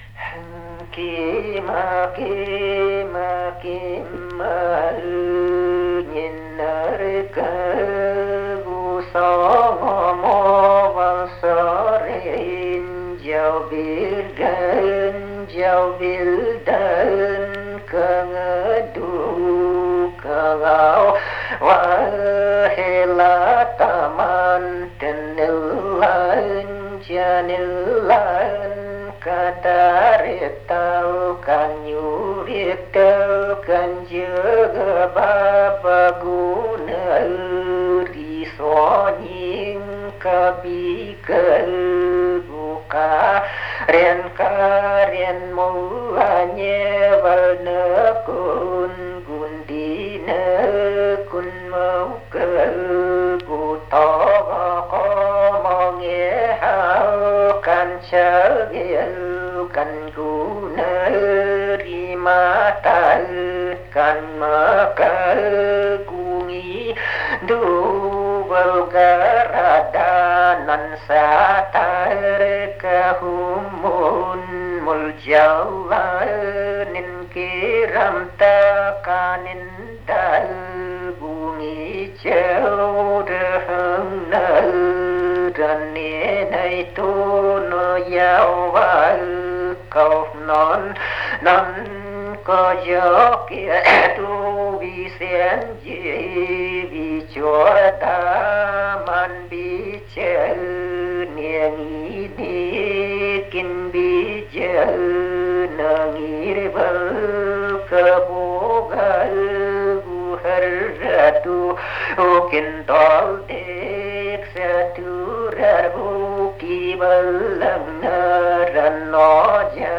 The Evenk Heroic Tales.
Because both text were originally recorded without use of any audio recording devices, the accompanying record contains portions of audio from another epic, “The Hero Irkismondya”, as performed by the same storyteller.